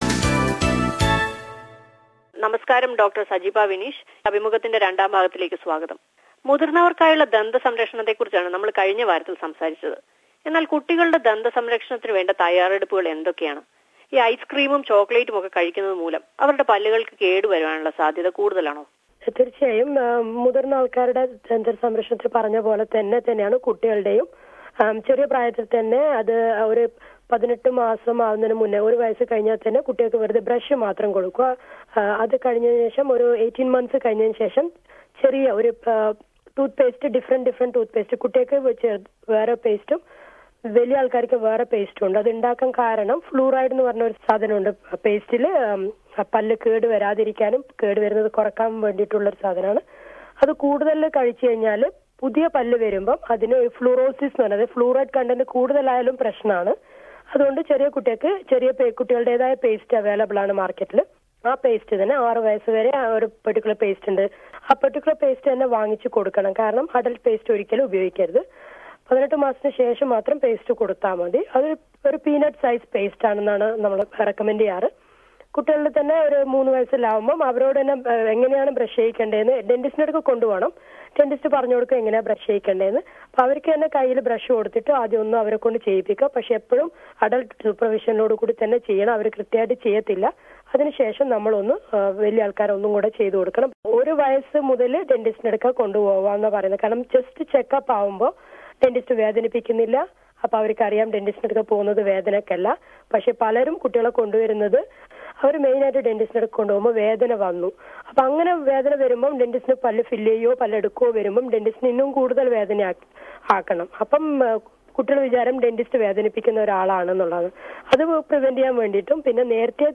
In the second part of the interview she is giving some tips for the dental care of children....